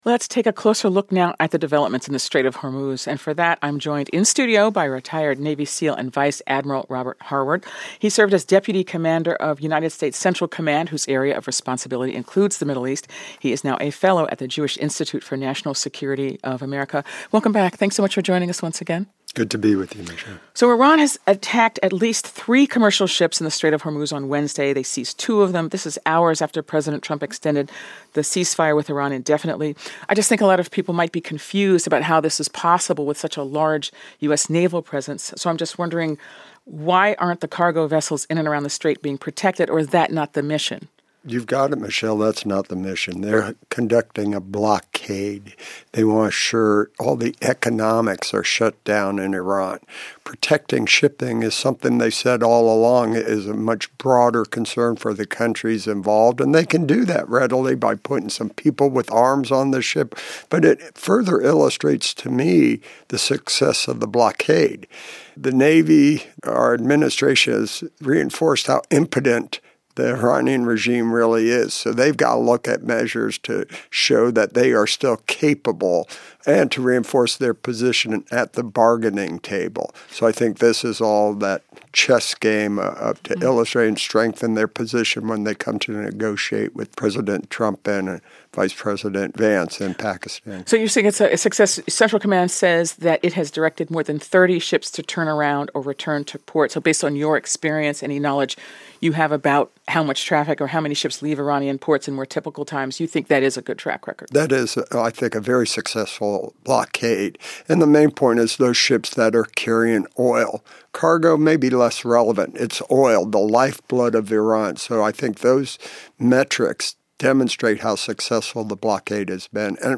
JINSA Iran Policy Project Advisor VADM (ret.) Robert Harward, former Deputy Commander of U.S. Central Command, joined Morning Edition on NPR to discuss the U.S. naval blockade on Iran and the future of U.S. policy on Iran.